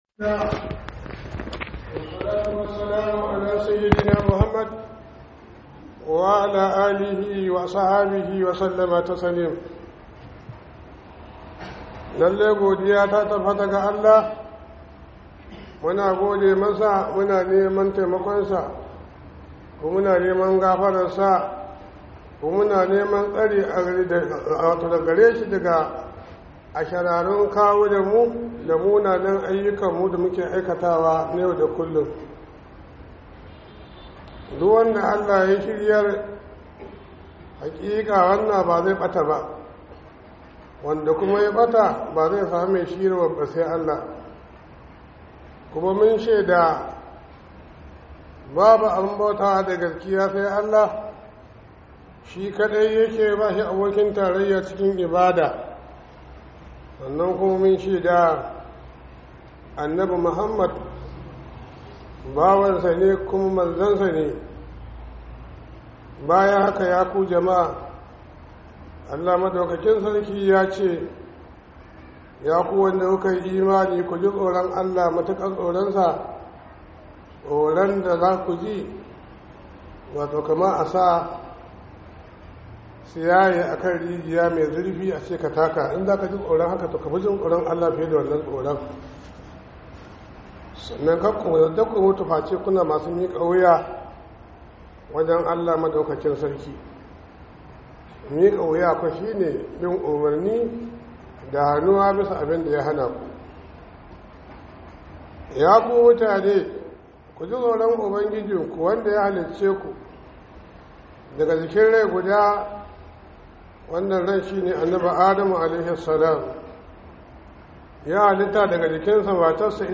Khudubar Sallar Juma'a by JIBWIS Ningi
Khuduba